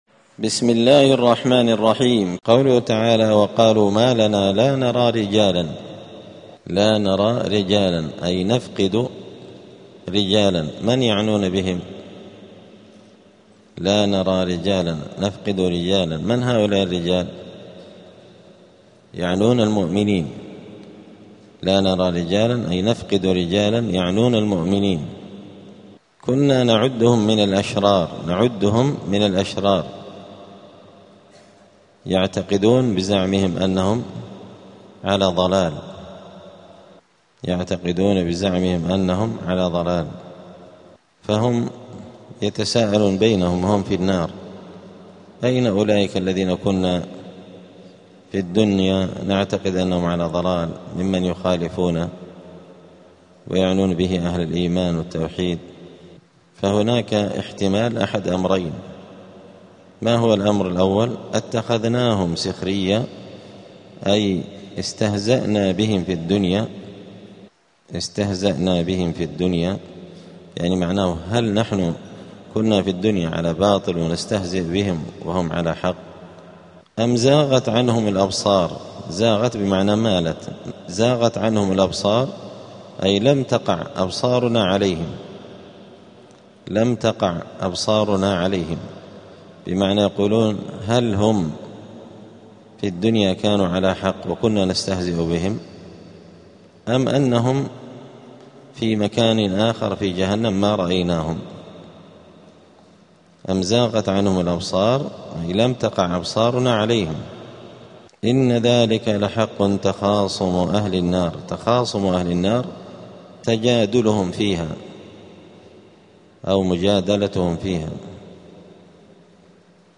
الأثنين 12 جمادى الأولى 1447 هــــ | الدروس، دروس القران وعلومة، زبدة الأقوال في غريب كلام المتعال | شارك بتعليقك | 5 المشاهدات